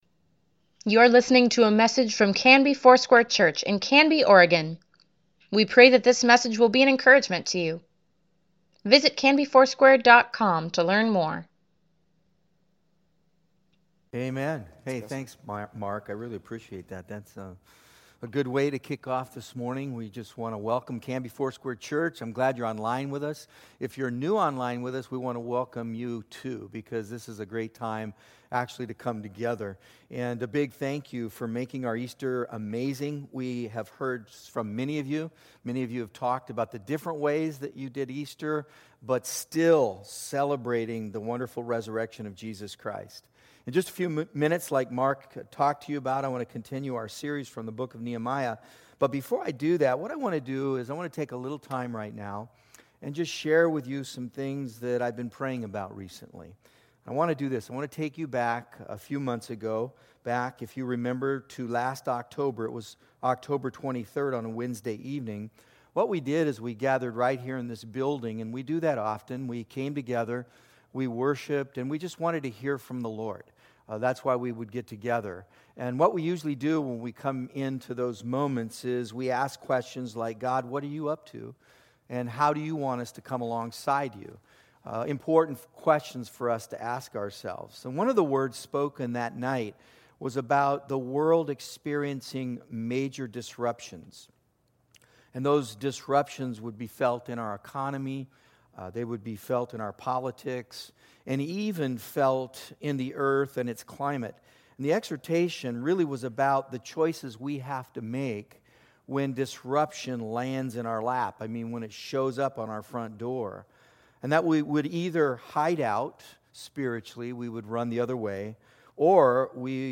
Weekly Email Water Baptism Prayer Events Sermons Give Care for Carus Nehemiah 7 - Celebrating Team April 19, 2020